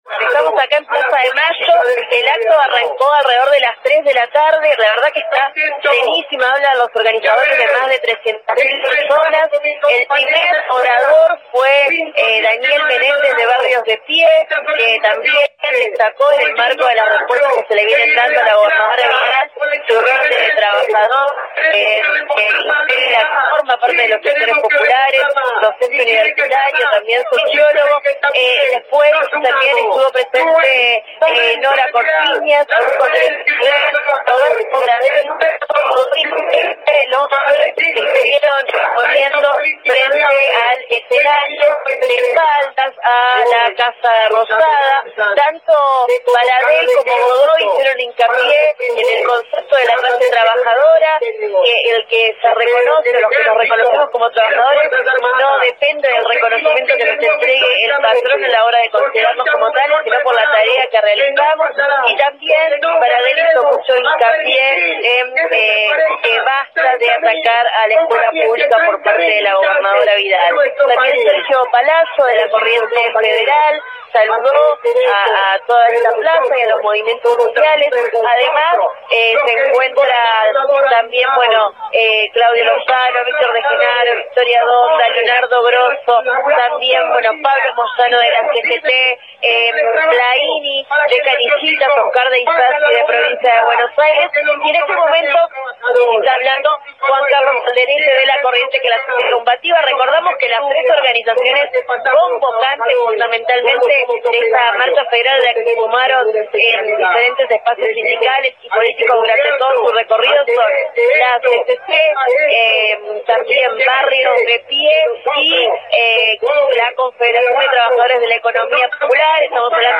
Una multitud concentró en la capital del país.
Radio Estación Sur acompañó la protesta y recopiló algunas de las voces de la jornada.